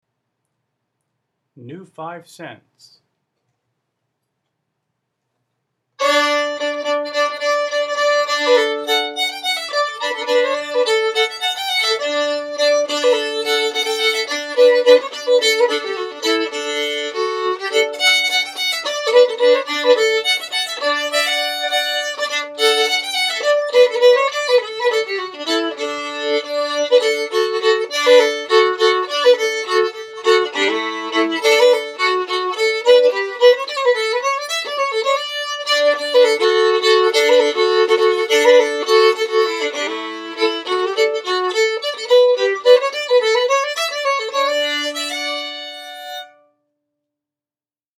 Set 4: Songs 61-117 (Advanced Arrangements)